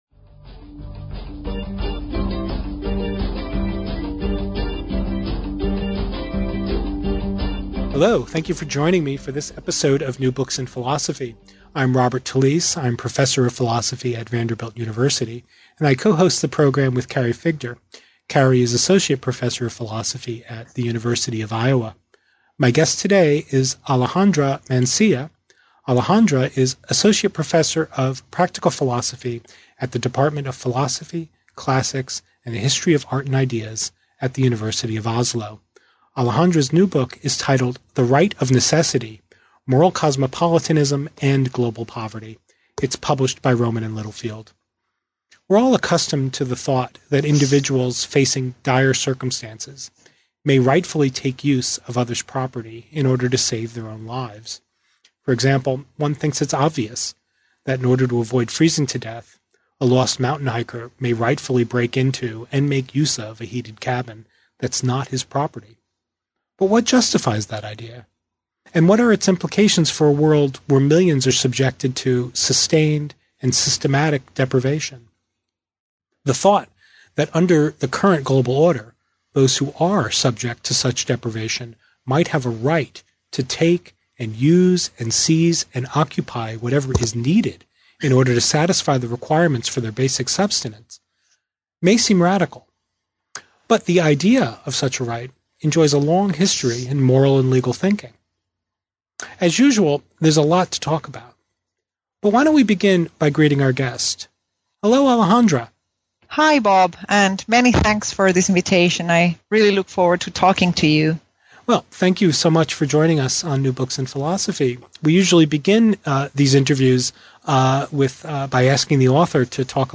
1708-interview-philosophy-now.mp3